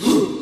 Vox (9).wav